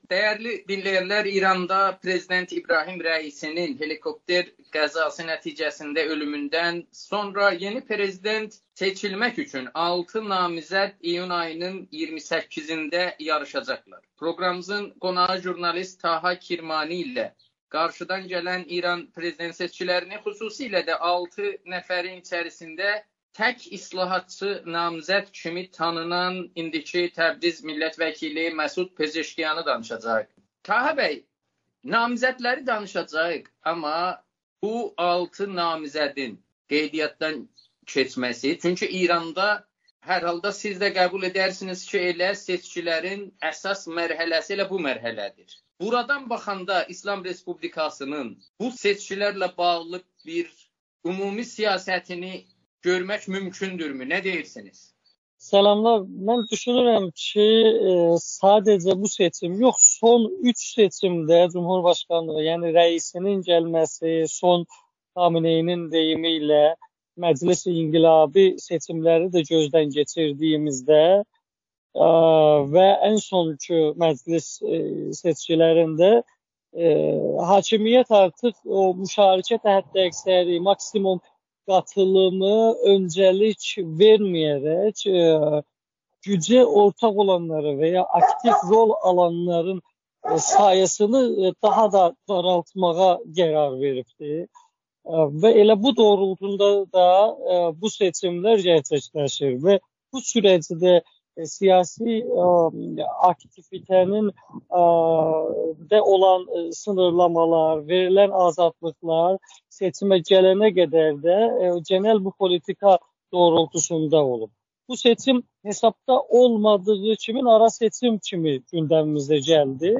İbrahim Rəisi və digər rəsmilərin ölümü ilə nəticələnə helikopter qəzasından sonra İranın nəzarət şurası tərəfindən prezidentliyə namizədliyi təsdiqlənən 6 siyasətçi iyunun 28-də ölkədə keçiriləcək prezident seçkilərində yarışacaqlar. Jurnalist